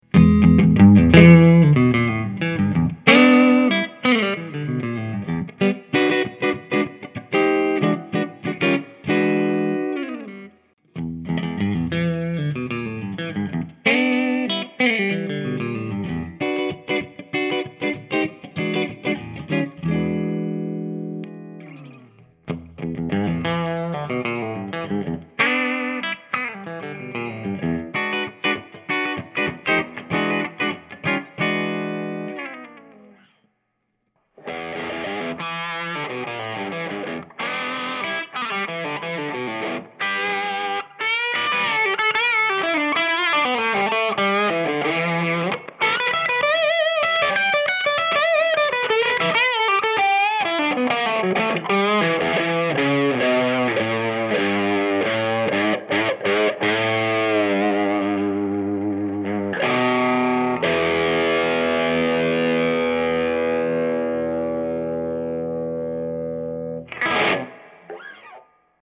New Spalt Nouveau Series Mucha La Plume Mahogany/Myrtle/Maple - Dream Guitars
P-90s with Alnico Magnets in Bridge and Ceramic Magnets in Neck.